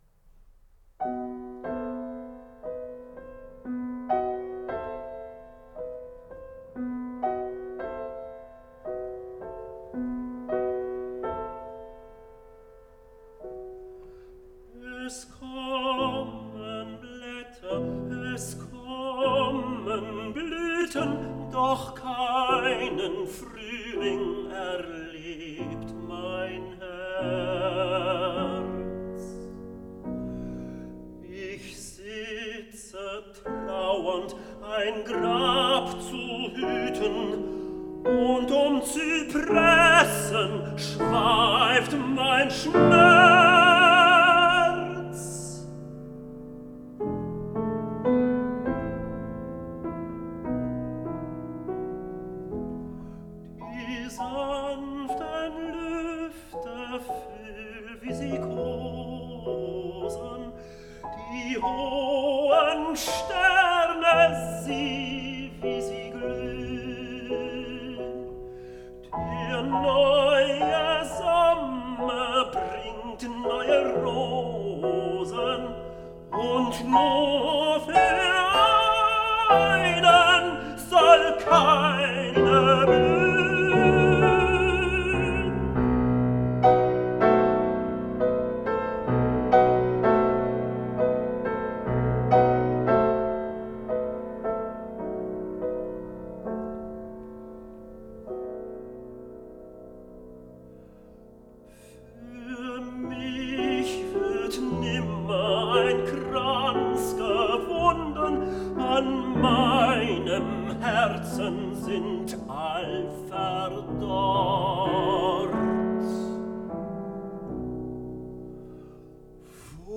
üppige Klangwelt des österreichischen Impressionismus